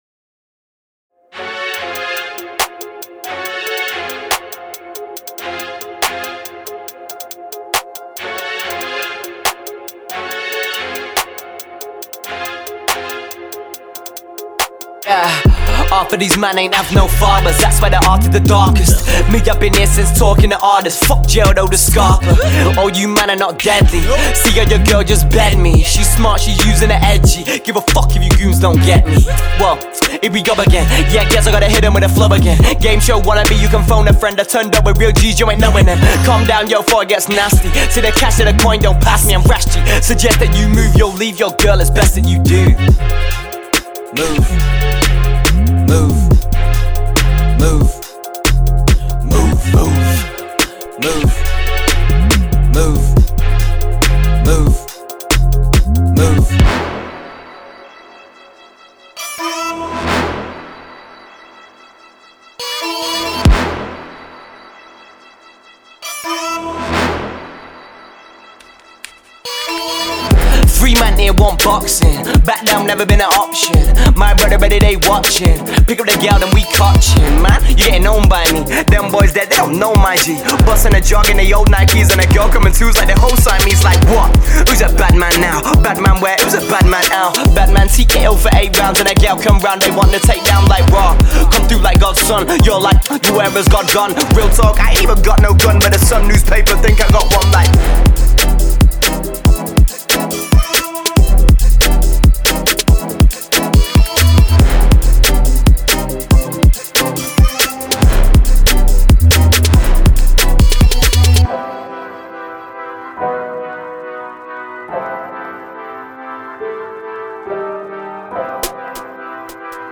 Hip HopTrap
巧妙的文字游戏，激烈的管弦乐演奏，引人注目的合成器和生病的鼓声相结合，创造出适合征服街道和无线电波的样本包。
请注意，此包的演示轨道是明确的，因此在收听时建议谨慎。
这款无拘无束的唱片集可以捕捉英国最真实的城市风格的声音。
每个Construction Kit都包含一个主要人声，adlib和伴奏的人声，为您提供更多选择和更多危险。